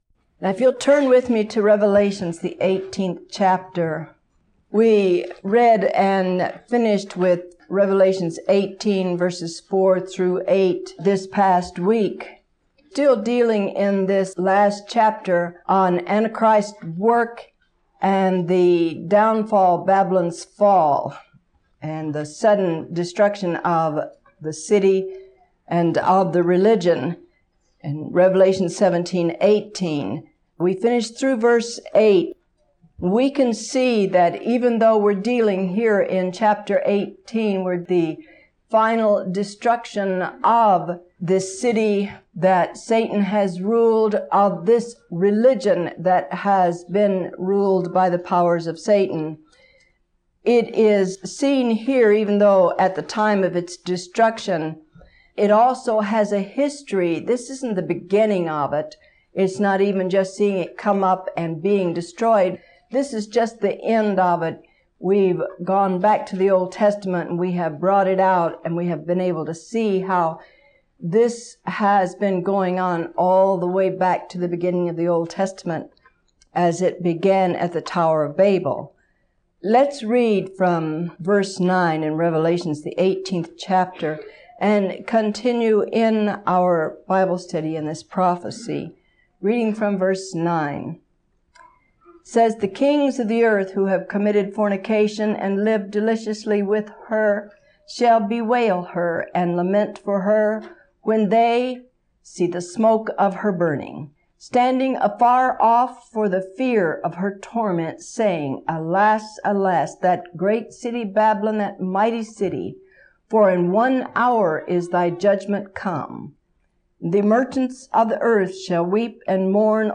February 18, 1987 – Teaching 58 of 73